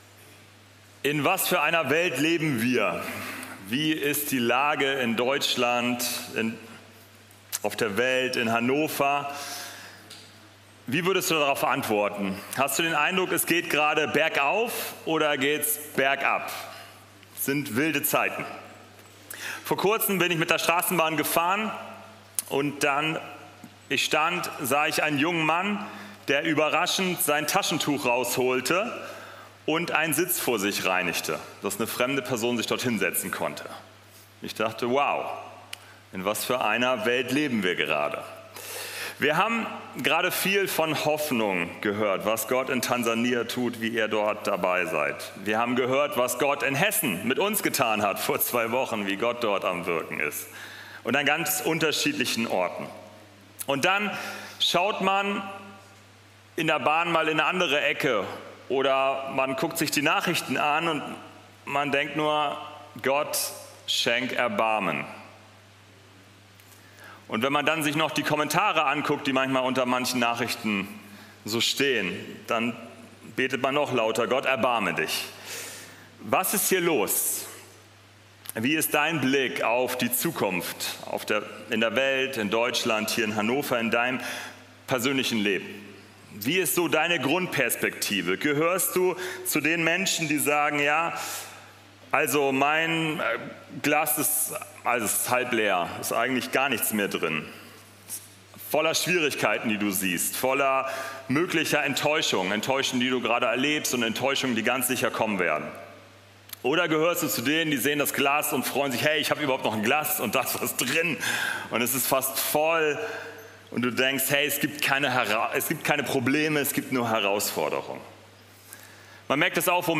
Gottesdienst